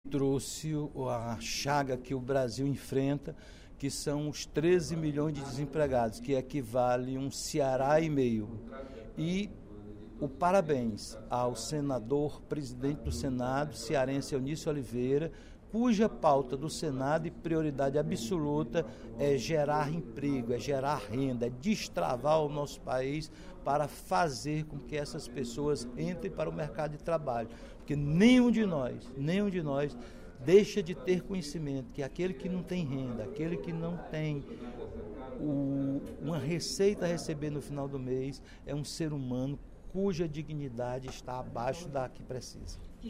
O deputado Roberto Mesquita (PSD) lamentou, nesta sexta-feira (10/03), durante o primeiro expediente da sessão plenária da Assembleia Legislativa, “a chaga” do desemprego, que assola o Brasil nestes últimos anos.